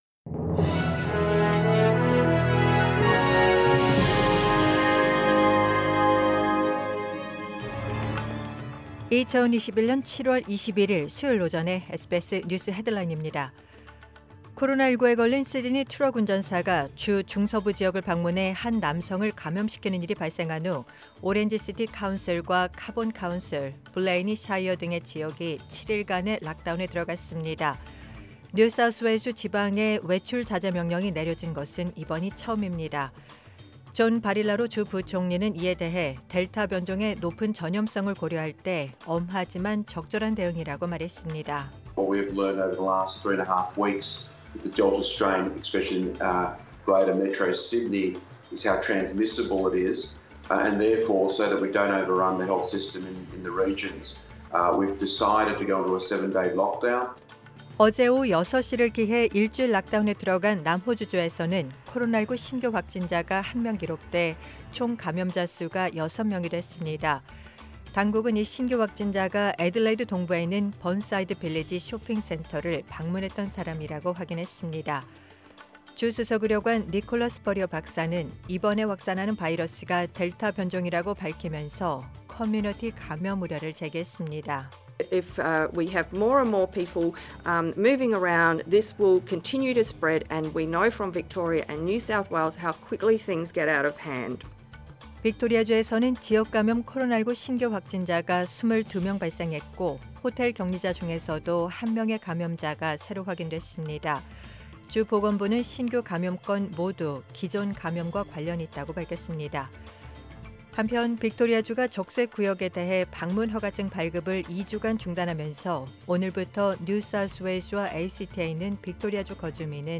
2021년 7월 21일 수요일 오전의 SBS 뉴스 헤드라인입니다.